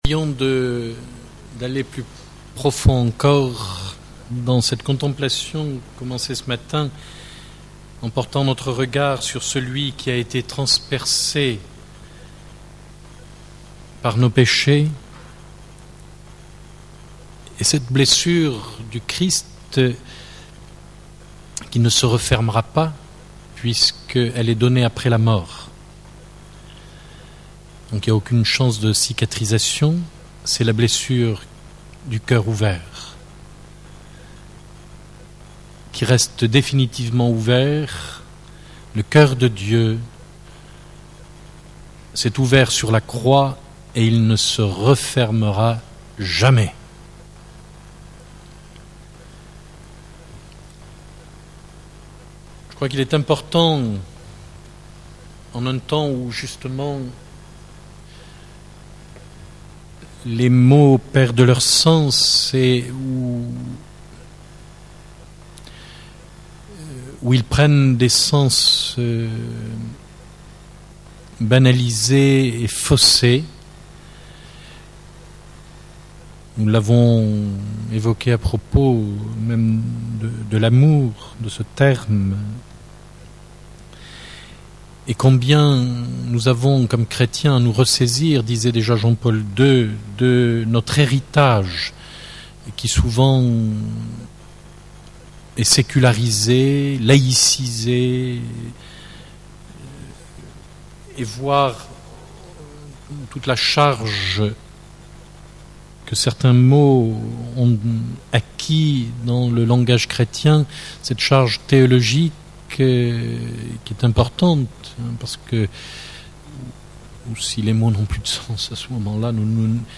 Enseignement
Récollection pour tous du mardi 27 mars au dimanche 1°avril 2007